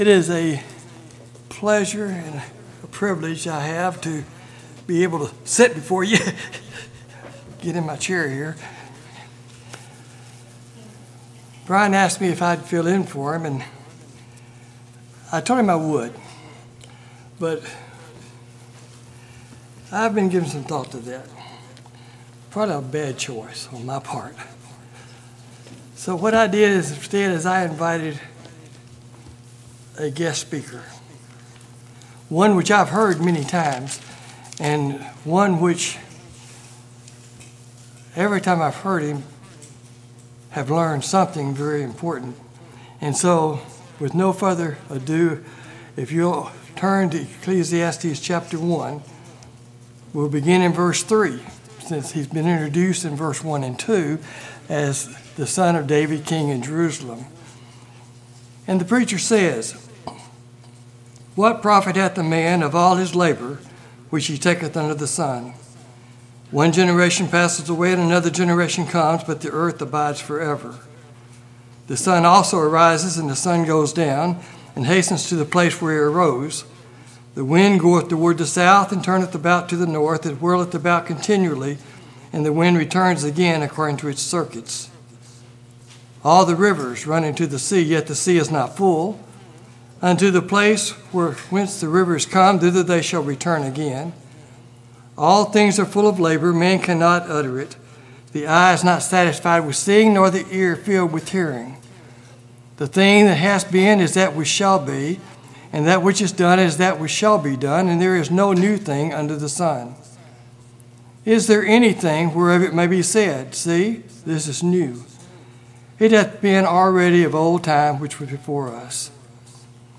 A reading of Ecclesiastes